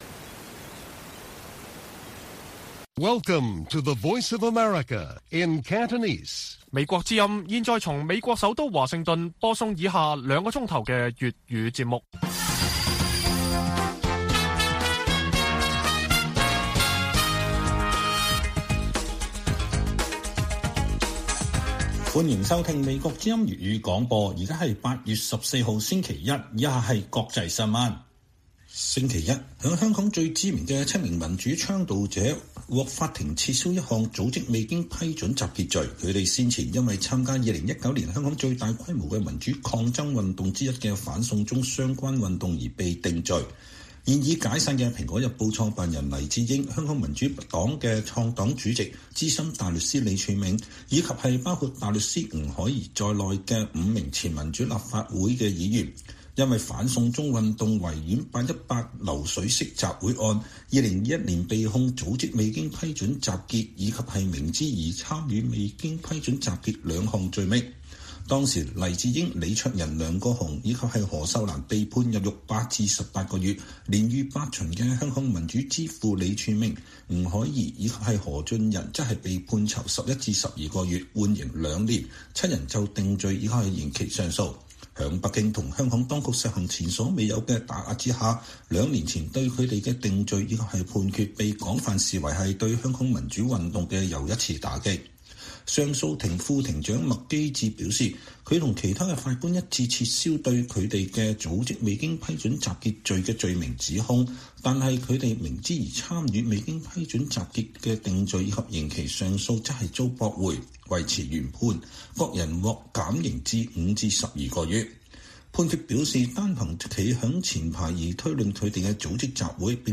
粵語新聞 晚上9-10點: 李柱銘黎智英等7名香港民主派反修例組織集結上訴得直